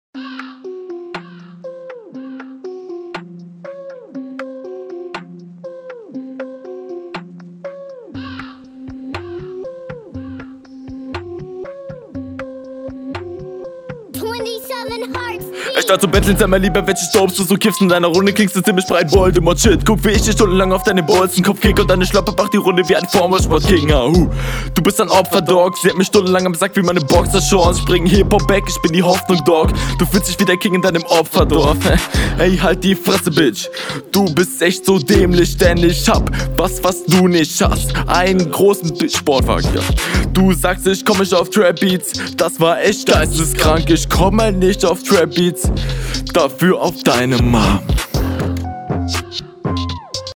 flow routinierter als die hr delivery nicht ansatzweiße so stylisch lines sehr unspektakulär die endline …